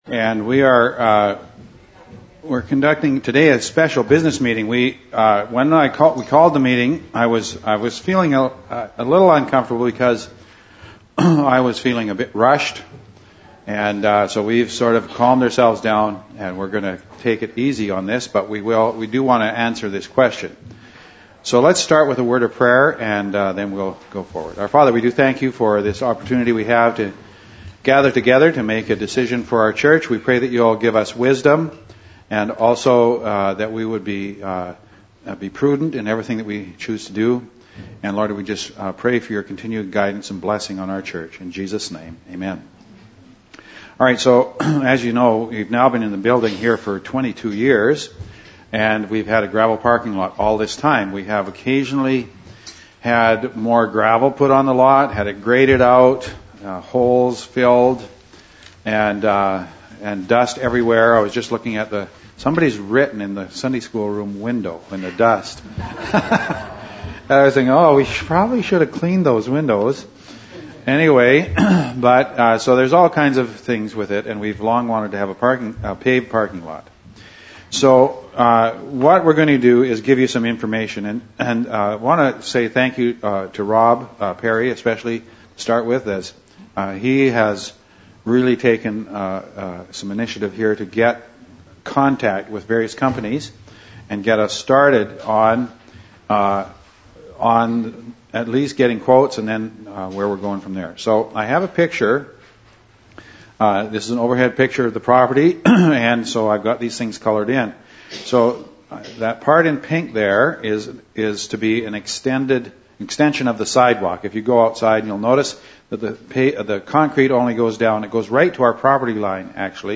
In this session, we hold a special business meeting to discuss paving our parking lot. After the meeting, we spend some time refining our statement on the Doctrine of Man.